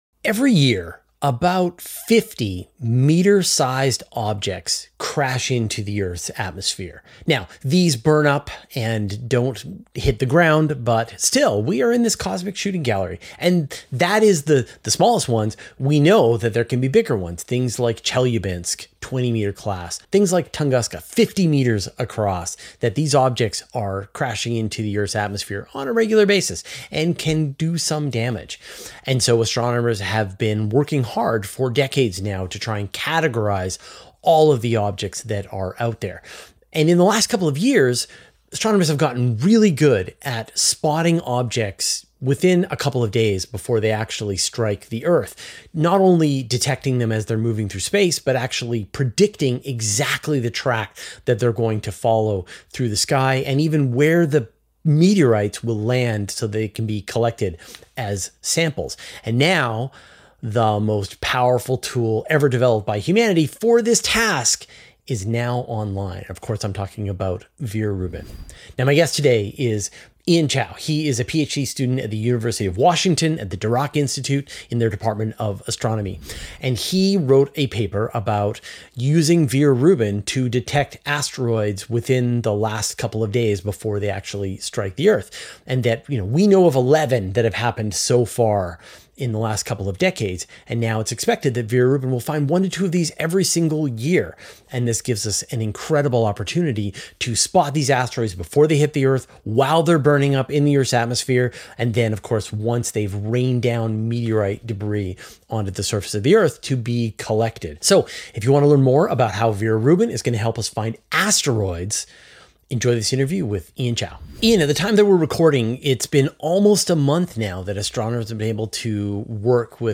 But now we have the most advanced tool to discover and predict them, the Vera Rubin Observatory. Will it be enough to predict a hit with enough precision to catch up a meteorite mid-flight? Finding out in this interview.